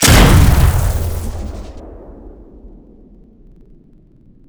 pistol2.wav